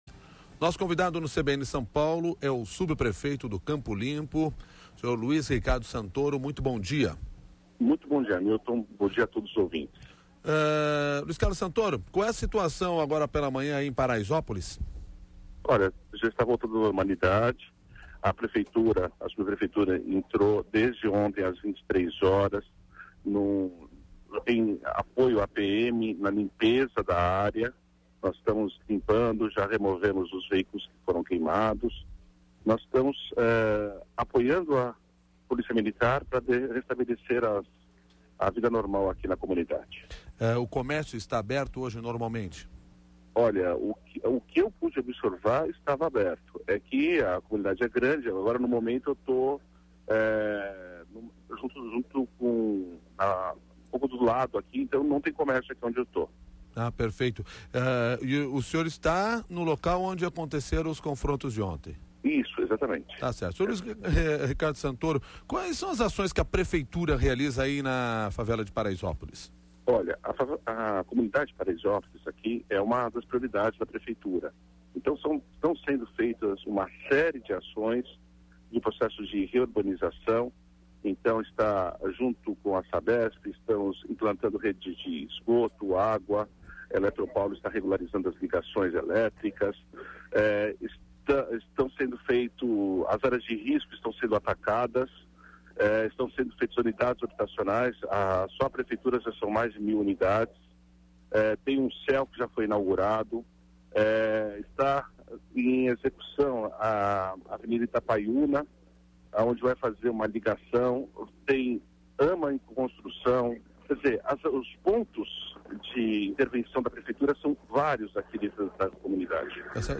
De acordo com o subprefeito do Butantã, Luiz Ricardo Santoro, a limpeza de córregos e a construção de cerca de mil unidades habitacionais são duas das ações que estão sendo realizadas neste momento. Ouça a entrevista com o subprefeito Luiz Ricaro Santoro .